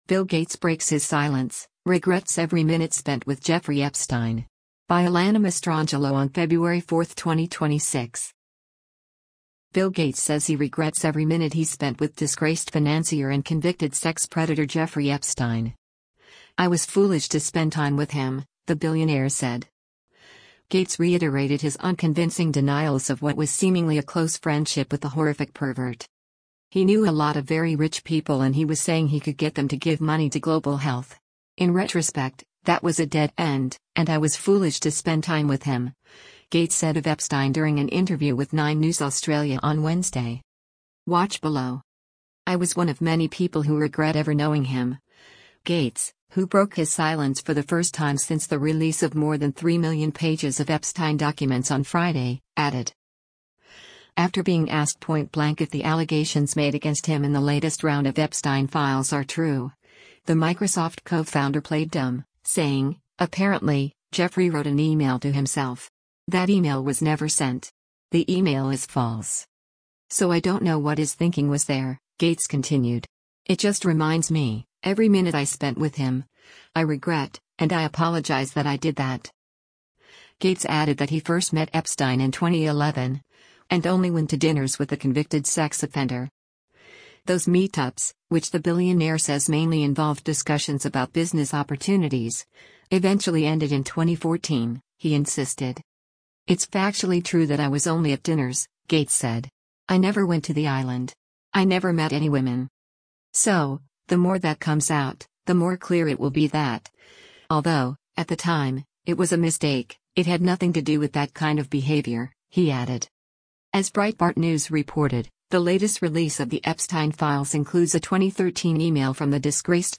“He knew a lot of very rich people and he was saying he could get them to give money to global health. In retrospect, that was a dead end, and I was foolish to spend time with him,” Gates said of Epstein during an interview with 9 News Australia on Wednesday.